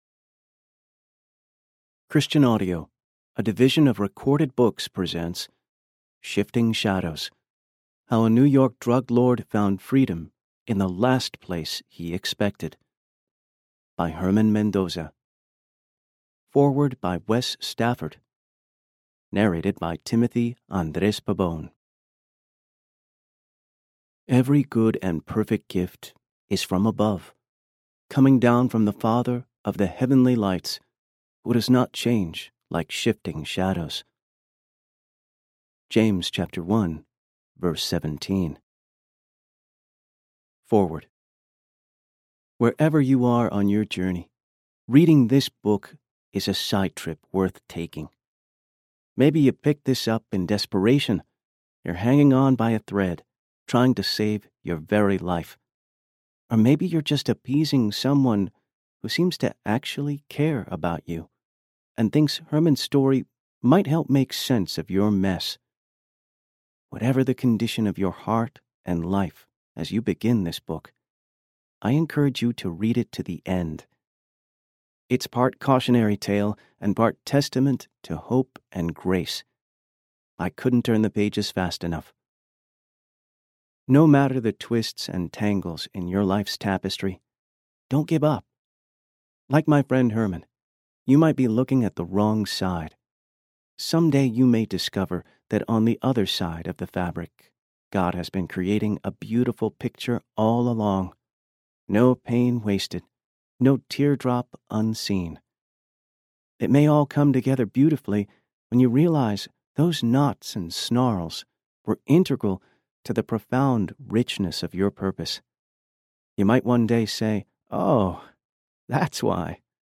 Narrator
7.3 Hrs. – Unabridged